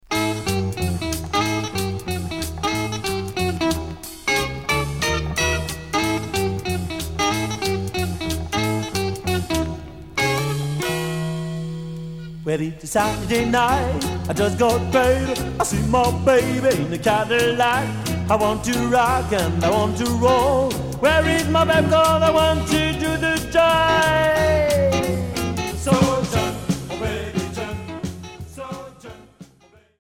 Rockabilly Premier 45t retour à l'accueil